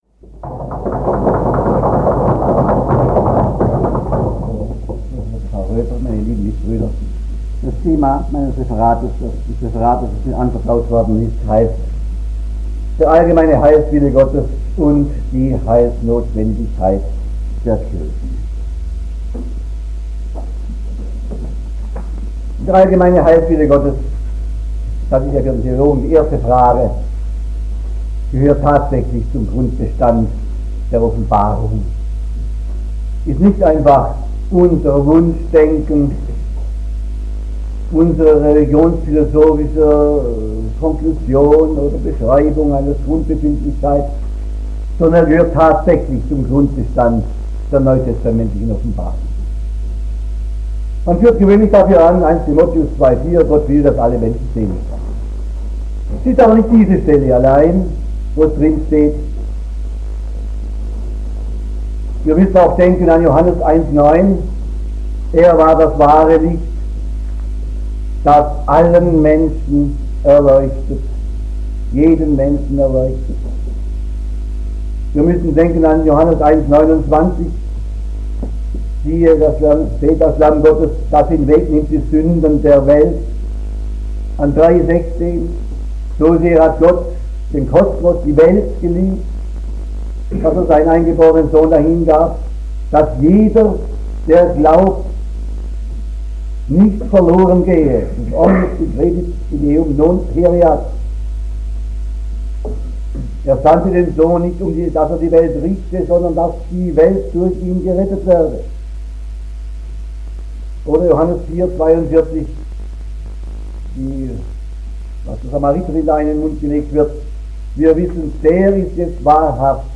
Der universale Heilswille und die Heilsnotwendigkeit der Kirche - Rede des Monats - Religion und Theologie - Religion und Theologie - Kategorien - Videoportal Universität Freiburg
April 1967 im Kurhaus Sand auf der Bühlerhöhe.